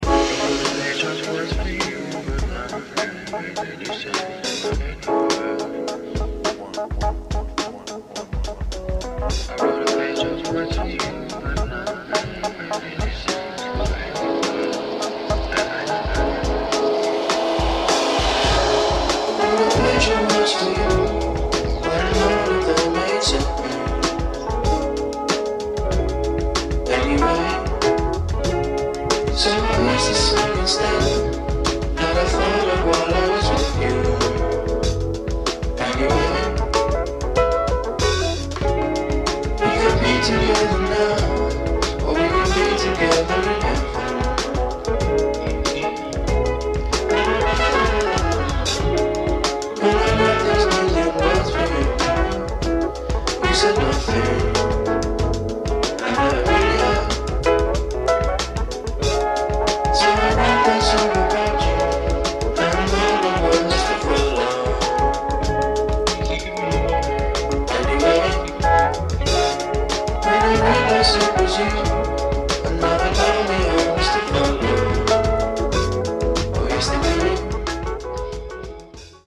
Album #2 from the young pianist.